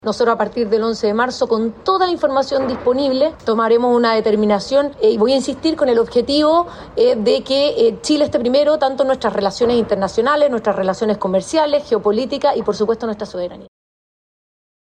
De hecho, la futura vocera, Mara Sedini, reiteró que cualquier determinación se adoptará una vez que asuman formalmente el gobierno, manteniendo así la incertidumbre al menos hasta el 11 de marzo.